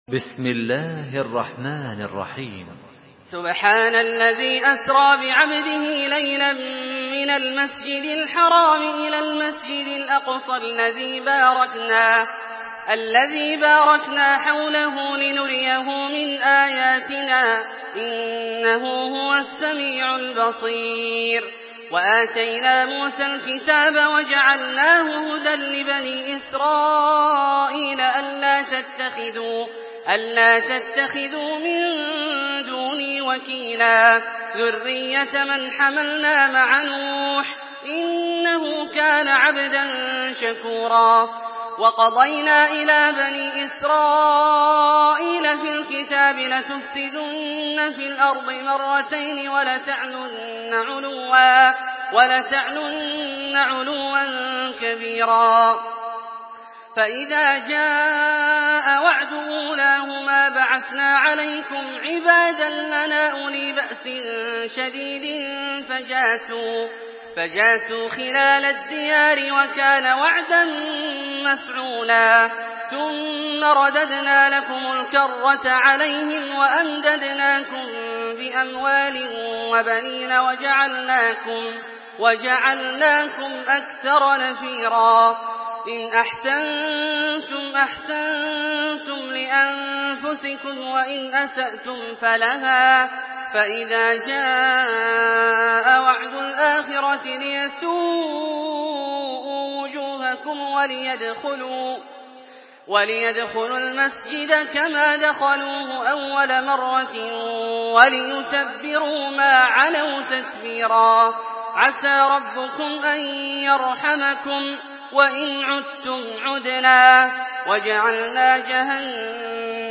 Surah আল-ইসরা MP3 in the Voice of Makkah Taraweeh 1427 in Hafs Narration
Murattal